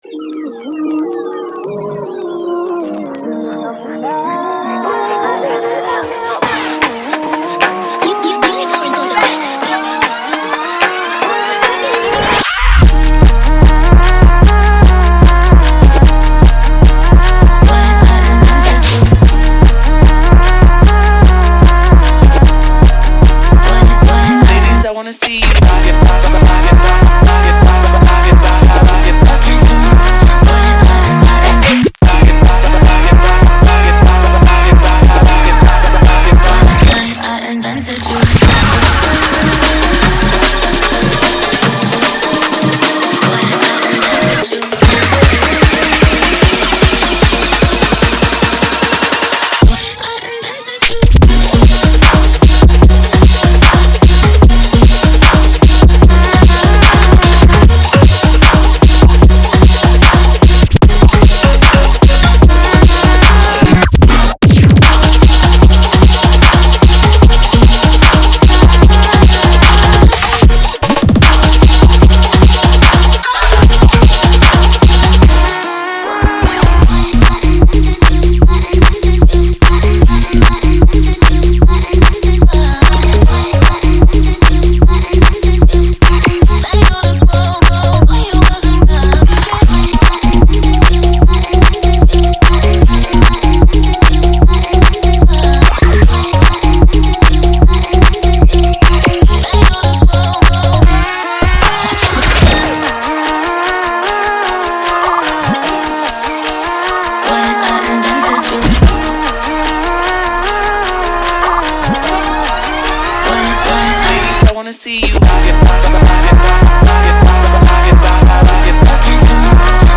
• Жанр: Dubstep